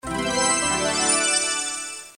SFX游戏胜利提醒素材音效下载
SFX音效